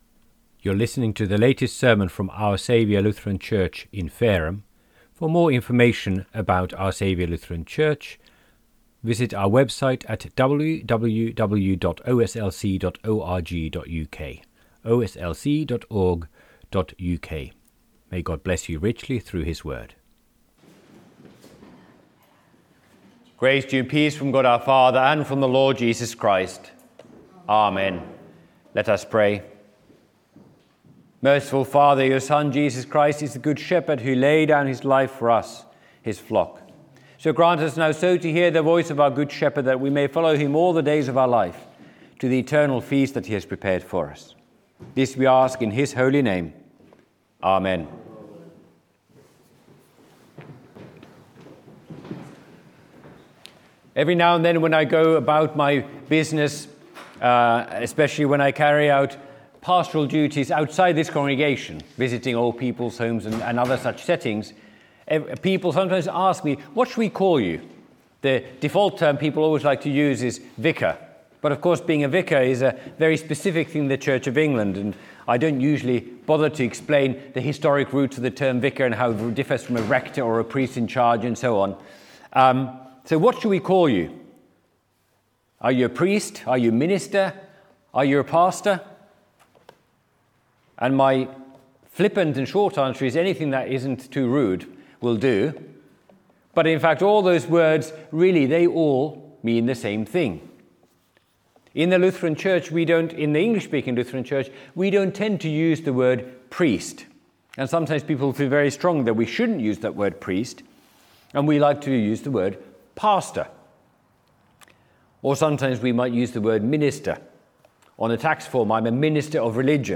by admin | Apr 19, 2026 | Sermons, Easter, Misericordias Domini